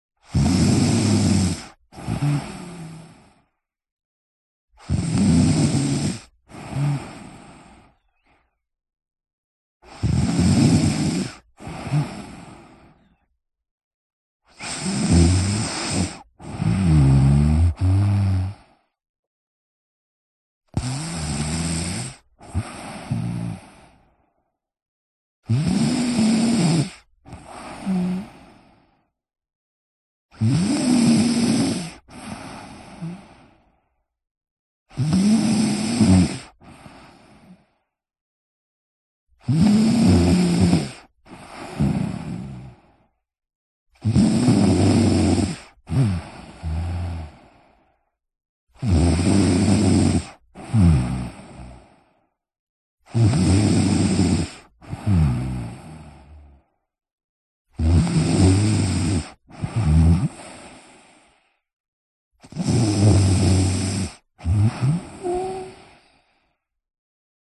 Звуки детского храпа
10 летний ребенок храпит по ночам